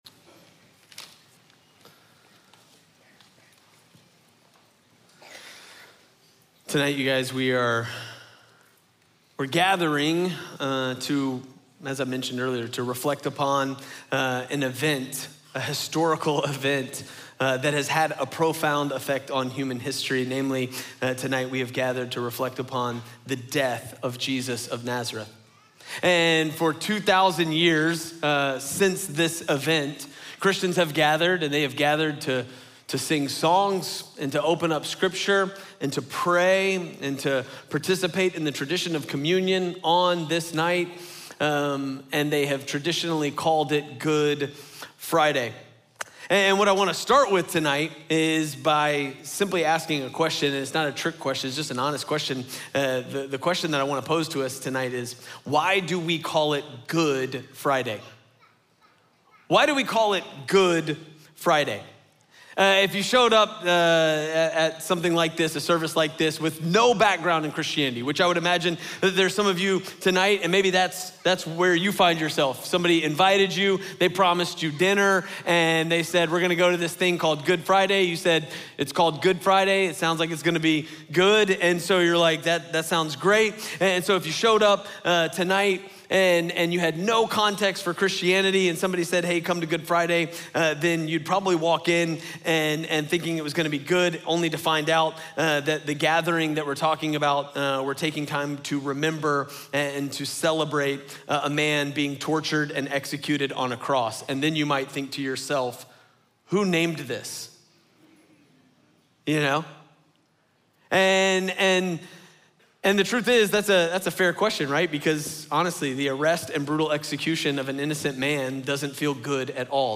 Good Friday at Gwinnett Church